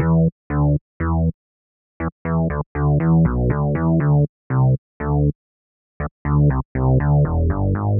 29 Bass PT2.wav